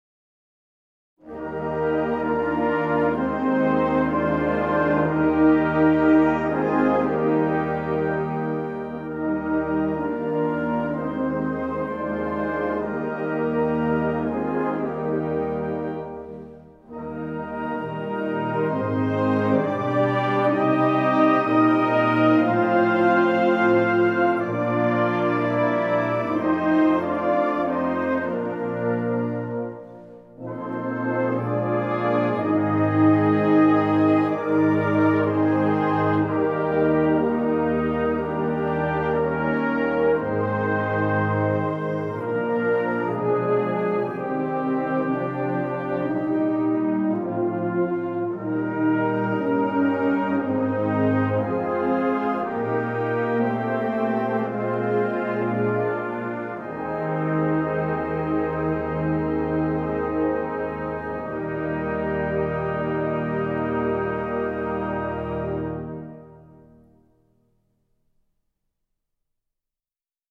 Gattung: Choralsammlung
Besetzung: Blasorchester